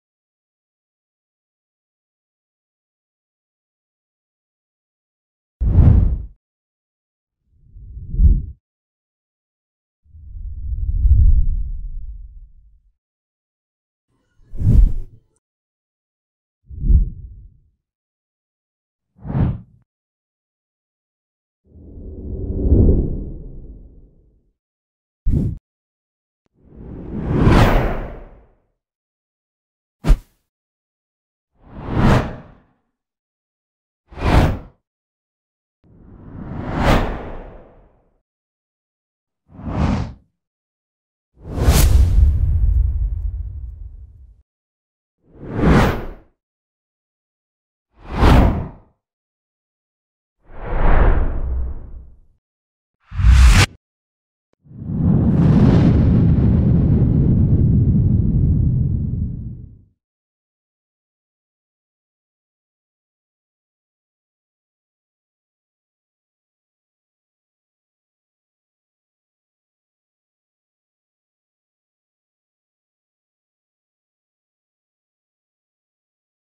20 FREE CINEMATIC WHOOSH Sound Effects (No Copyright).mp3